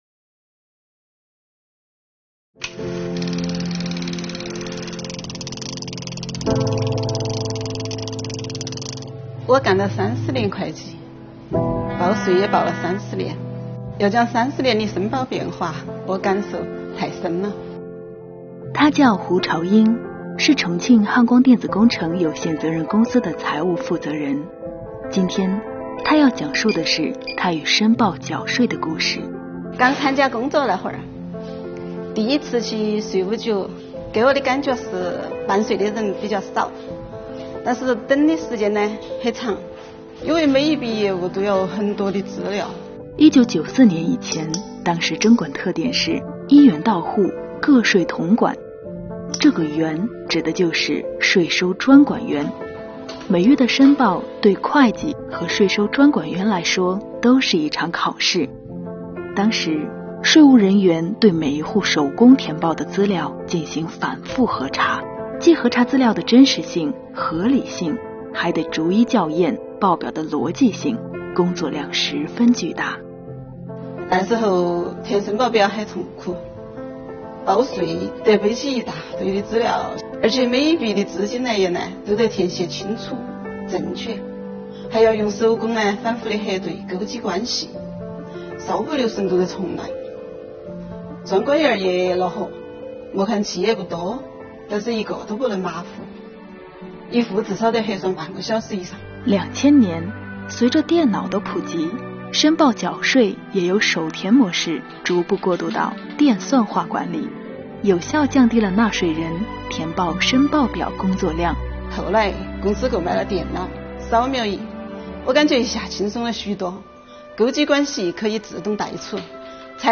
她干了30年会计，报税也报了30年，让我们一起来听听她与申报缴税的故事。